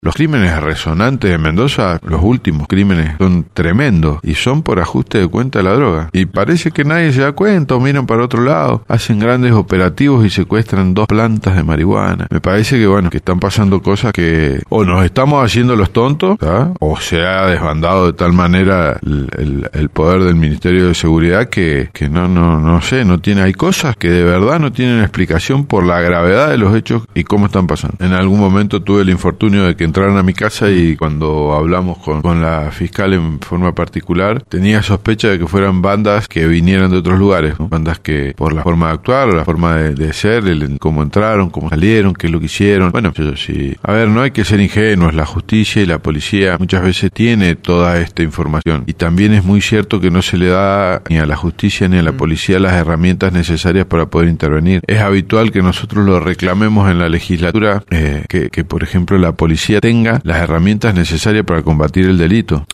Es más, durante una entrevista con LV18, calificó la problemática como «tremenda».